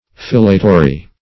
Search Result for " filatory" : The Collaborative International Dictionary of English v.0.48: Filatory \Fil"a*to*ry\, n. [LL. filatorium place for spinning, fr. filare to spin, fr. L. filum a thread.] A machine for forming threads.